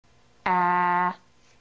・１〜４は サイト "Phonetics"のフラッシュから音声を抜き出したものです。
１．/aฺ/　（ネイティブスピーカー）